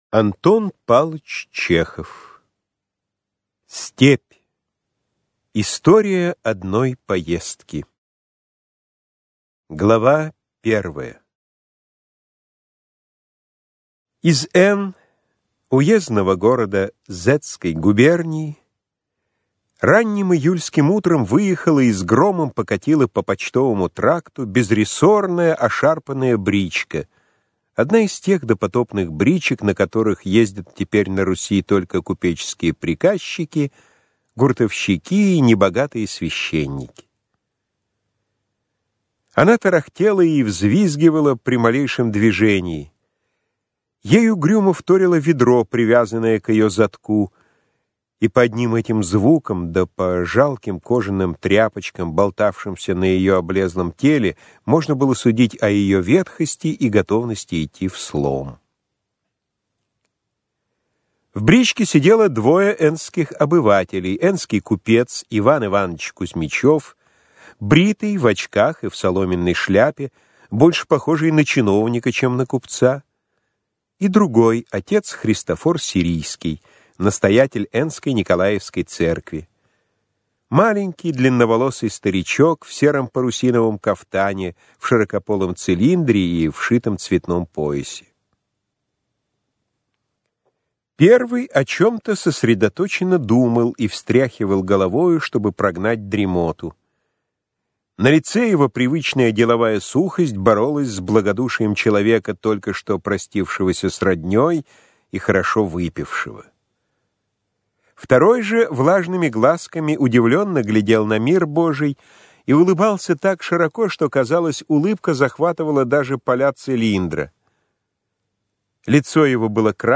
Аудиокнига Степь | Библиотека аудиокниг